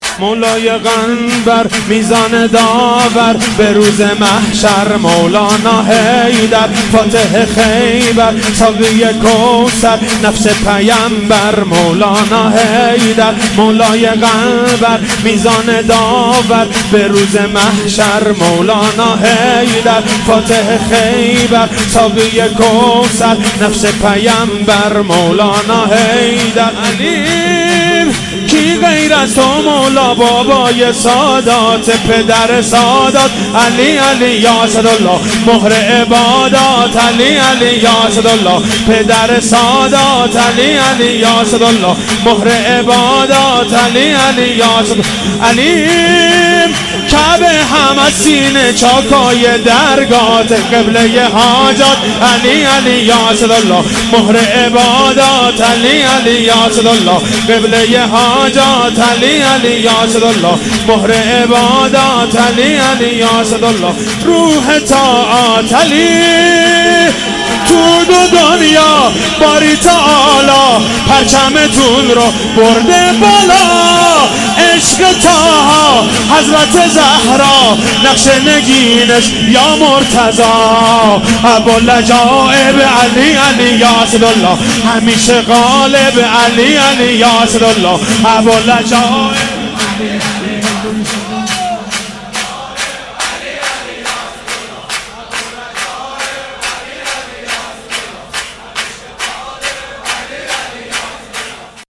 ولادت حضرت معصومه سلام الله علیها
شور مولودی